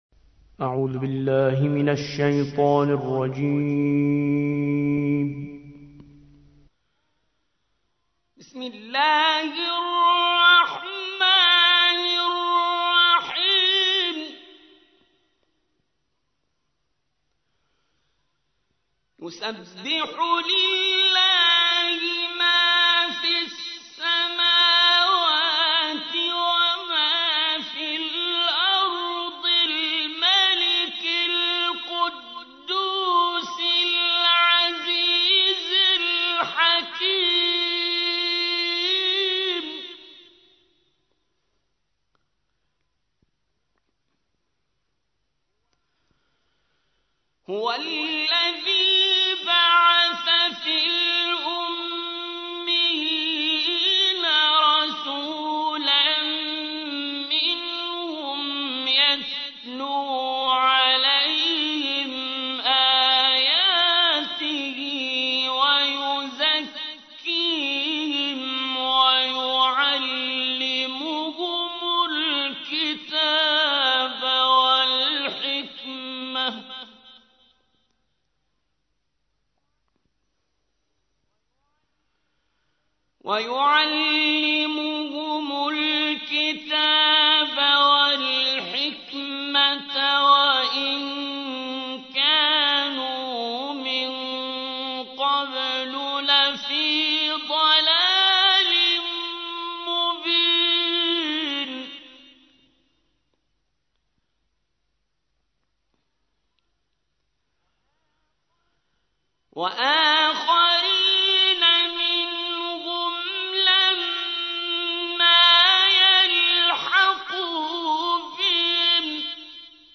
62. سورة الجمعة / القارئ